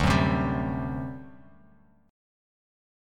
D11 Chord
Listen to D11 strummed